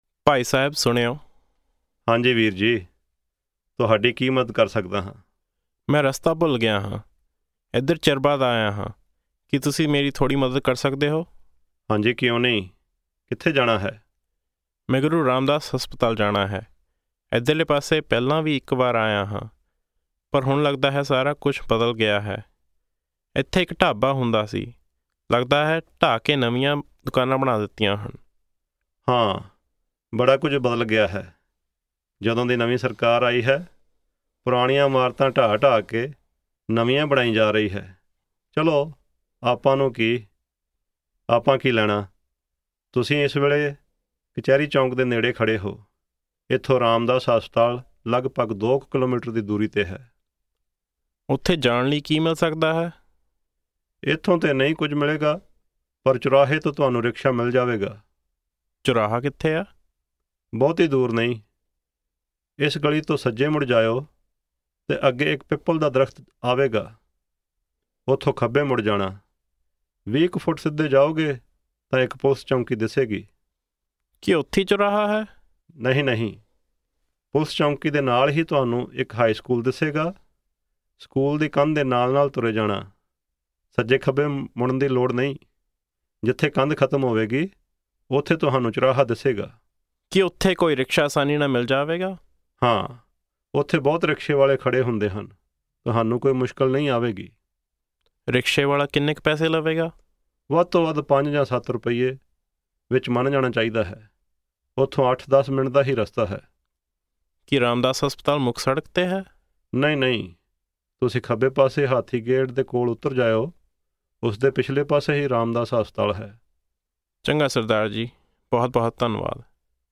Punjabi Conversation 5 Listen
Listen to these two men on the street. One is lost and needs some help. Listen to the particular use of landmarks to give directions.
asking_directions.mp3